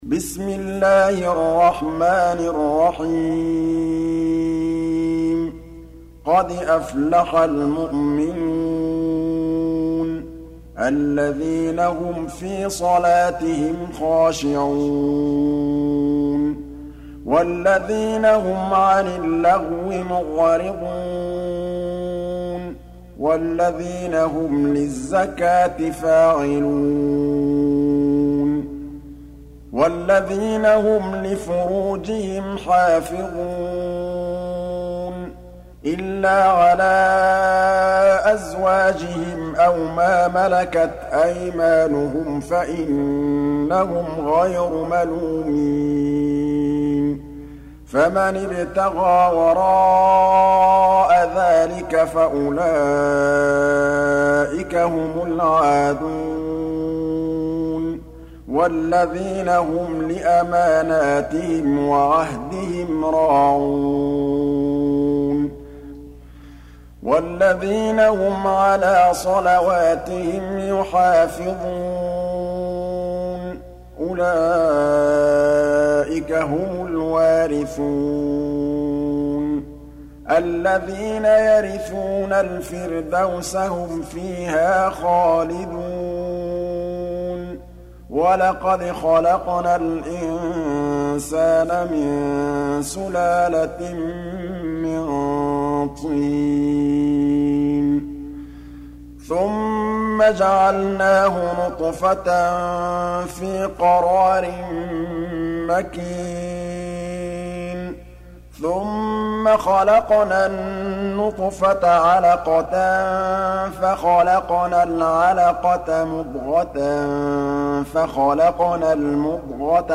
23. Surah Al-Mu'min�n سورة المؤمنون Audio Quran Tarteel Recitation
Surah Repeating تكرار السورة Download Surah حمّل السورة Reciting Murattalah Audio for 23. Surah Al-Mu'min�n سورة المؤمنون N.B *Surah Includes Al-Basmalah Reciters Sequents تتابع التلاوات Reciters Repeats تكرار التلاوات